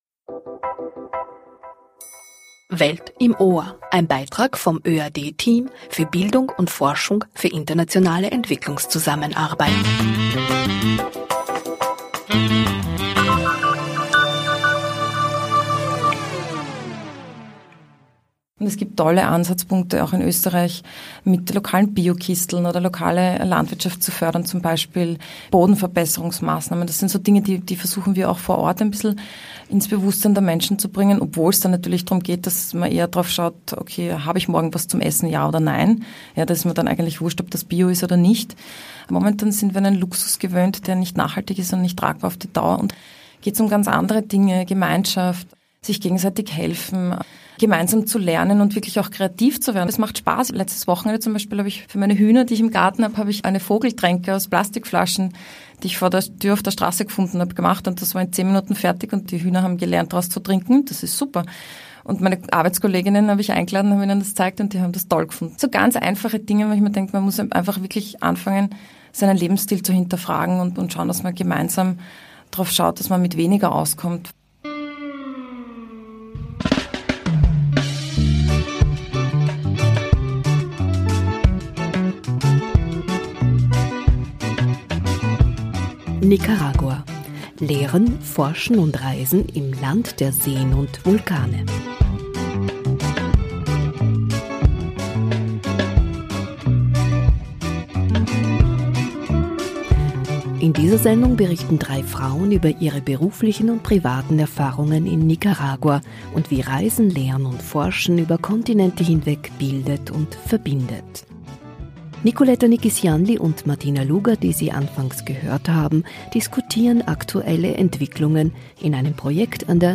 Nicaragua, ein Land voller Geheimnisse. Drei Frauen berichten über das Reisen, Forschen und Lehren in diesem so vielfältigen Land.